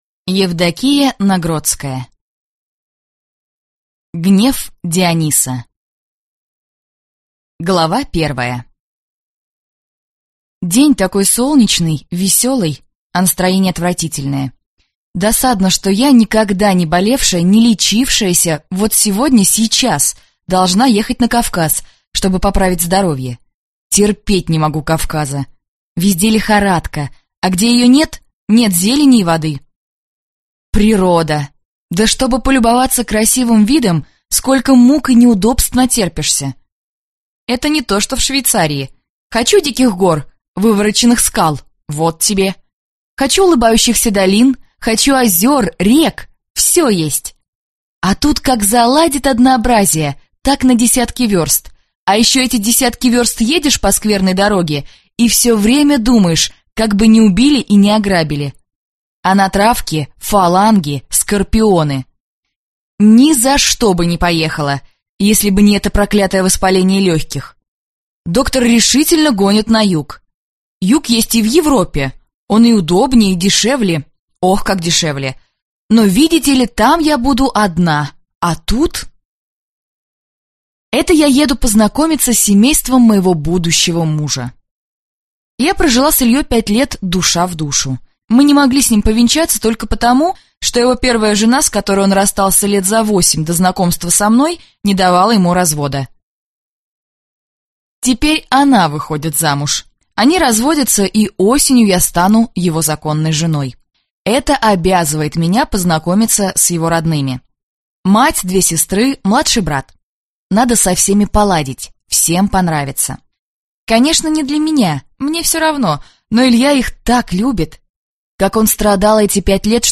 Аудиокнига Гнев Диониса | Библиотека аудиокниг